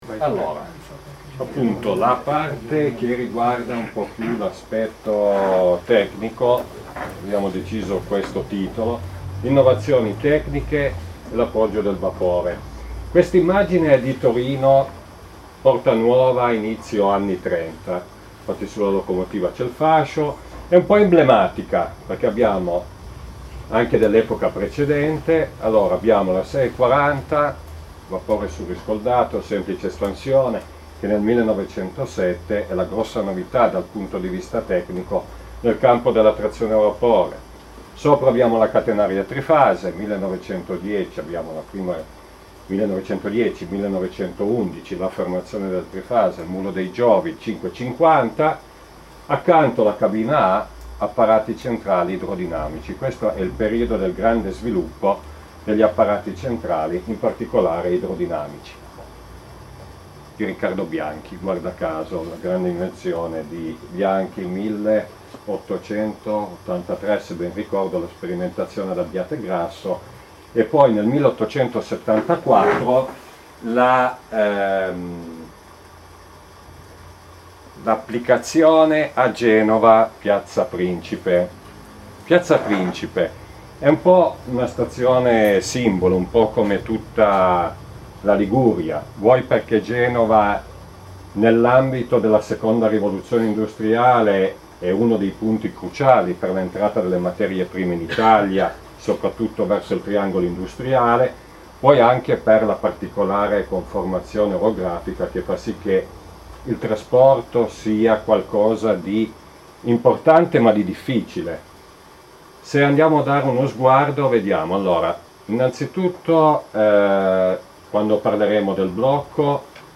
Sono disponibili le registrazioni audio delle conferenze del 21 settembre 2012: